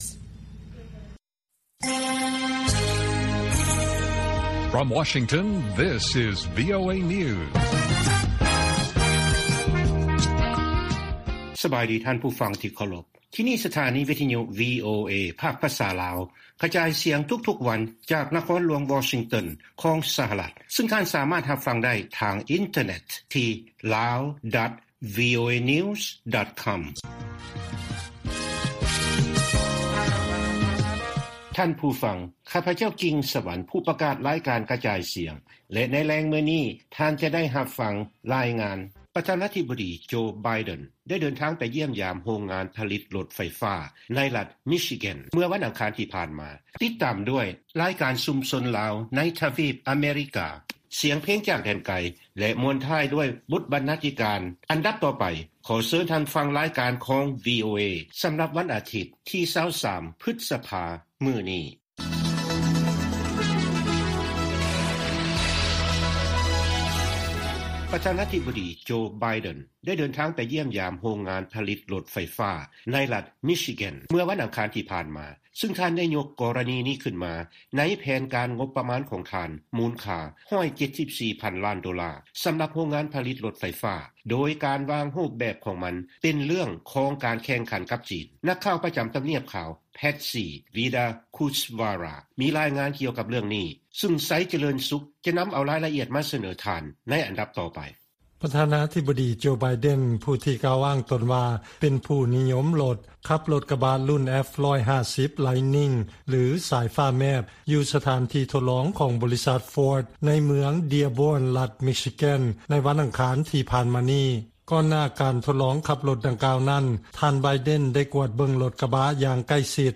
ລາຍການກະຈາຍສຽງຂອງວີໂອເອ ລາວ: ປະທານາທິບໍດີ ໄບເດັນ ພວມຊຸກຍູ້ ໃຫ້ສະຫະລັດ ເອົາຊະນະ ຈີນ ໃນການແຂ່ງຂັນ ດ້ານລົດໄຟຟ້າ